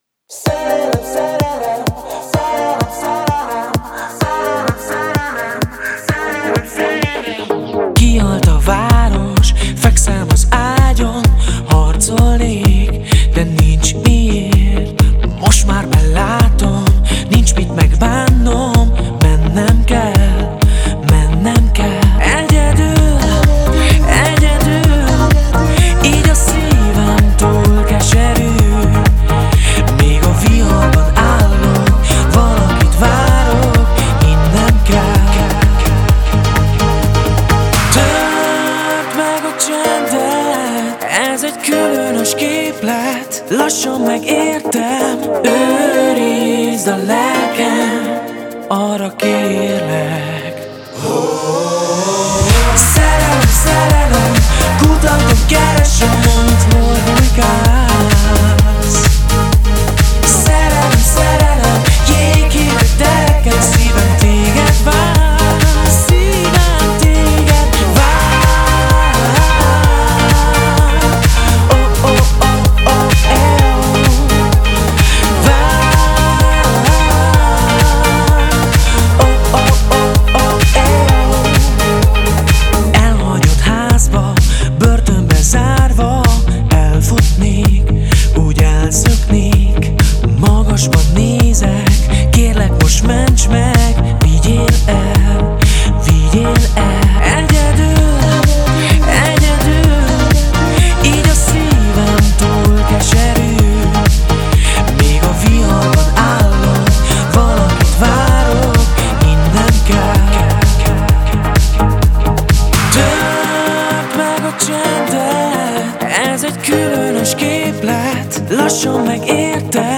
Radio version
Stílus: Pop